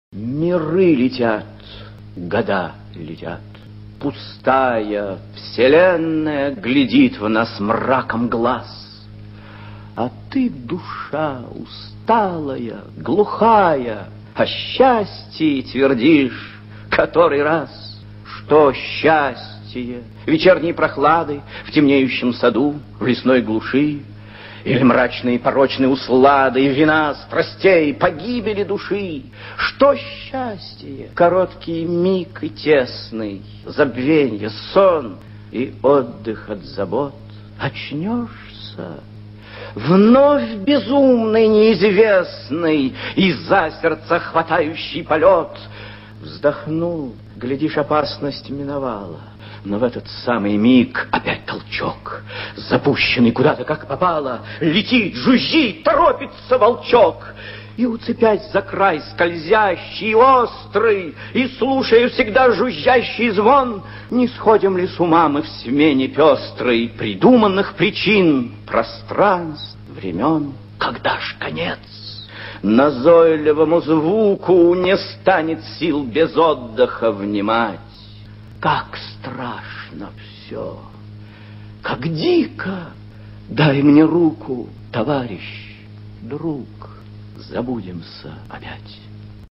Прослушивание аудиозаписи стихотворения «Миры летят. Года летят...» с сайта «Старое радио». Исполнитель А. Консовский.